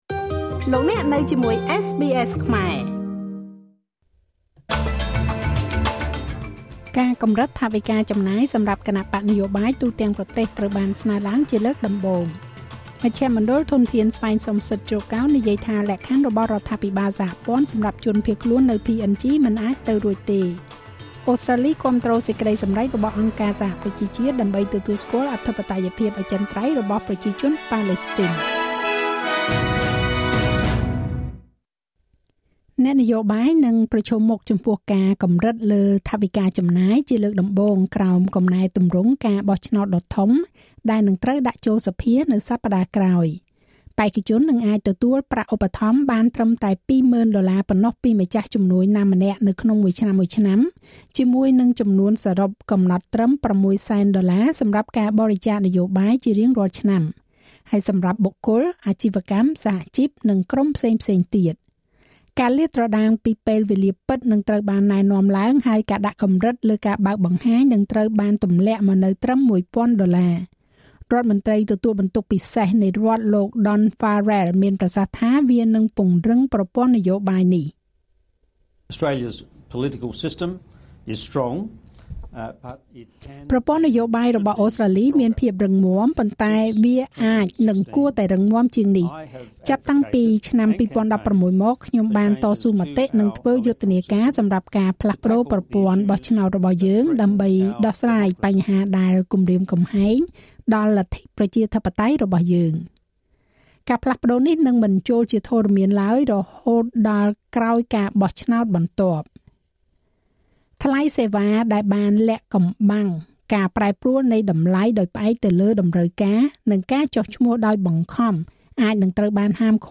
នាទីព័ត៌មានរបស់SBSខ្មែរ សម្រាប់ថ្ងៃសុក្រ ទី១៥ ខែវិច្ឆិកា ឆ្នាំ២០២៤